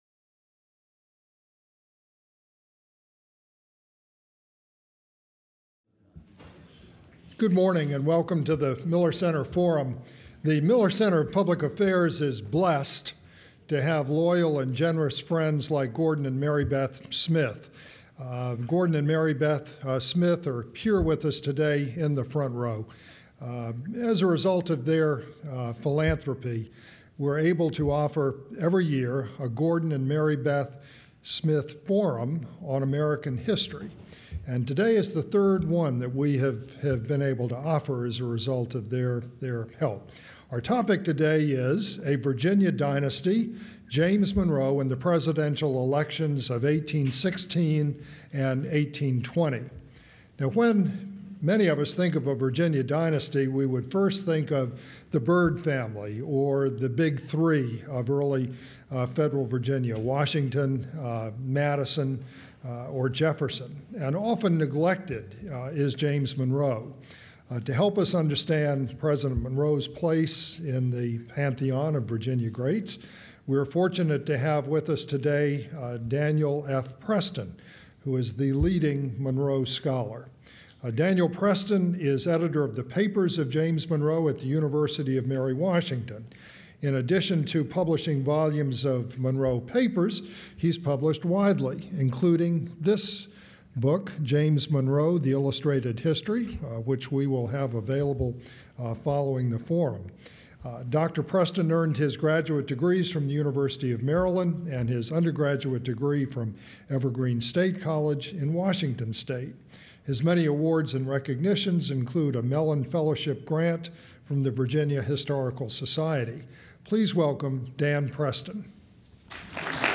This was the annual Gordon and Mary Beth Smyth Forum on American History.